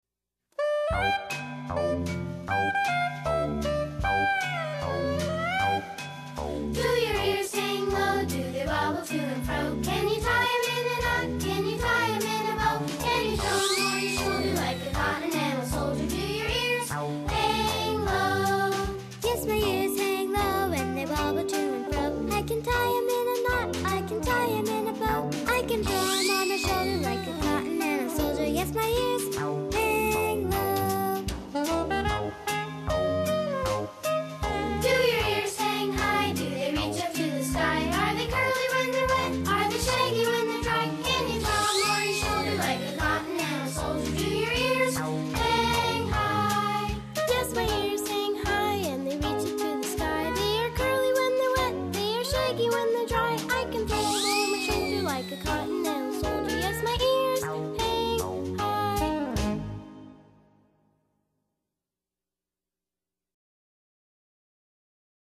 英语童谣